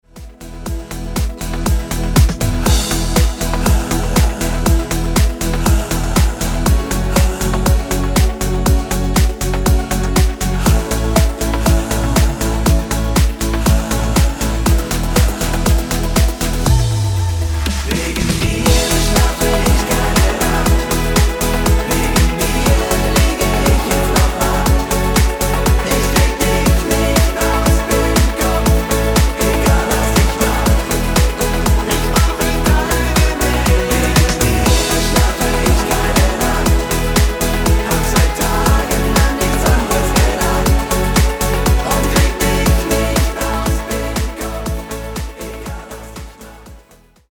--> MP3 Demo abspielen...
mit Backing Vocals